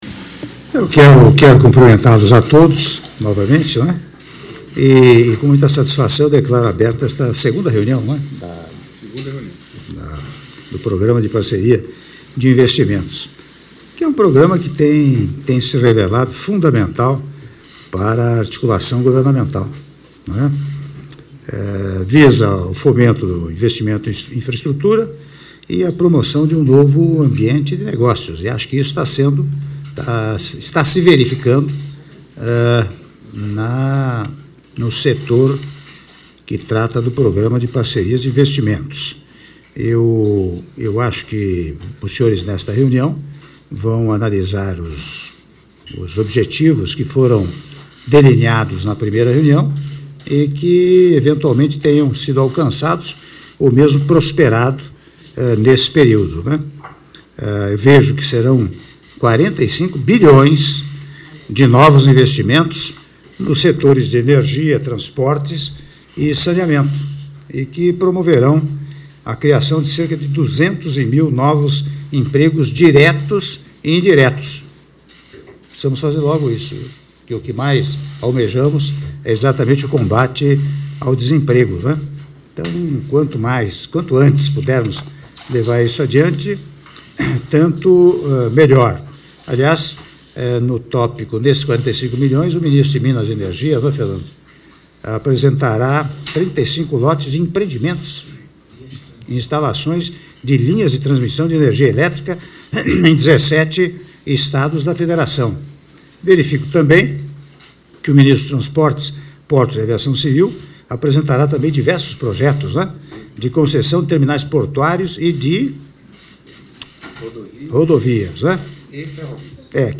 Áudio do discurso do presidente da República, Michel Temer, durante Reunião do Conselho do Programa de Parcerias de Investimentos (CPPI) - (05min41s) - Brasília/DF — Biblioteca